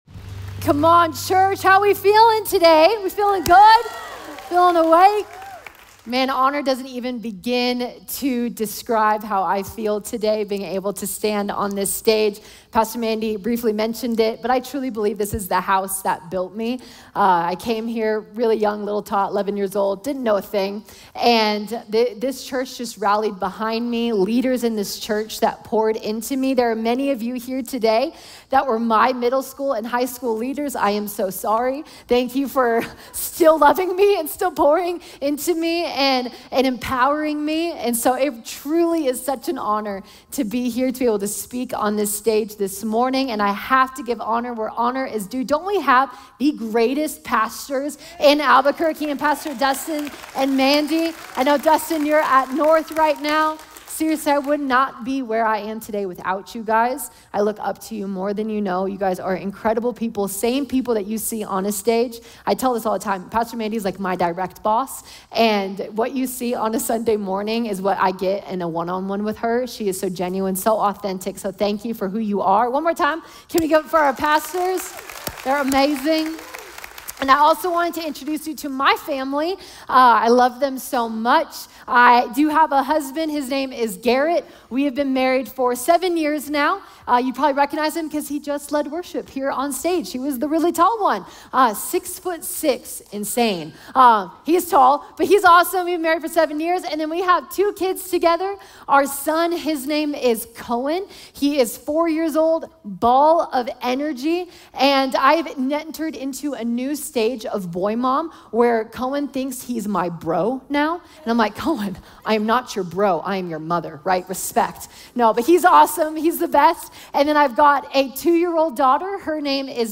Subscribe to the Citizen Church Podcast and automatically receive our weekly sermons.